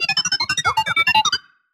beeps1.wav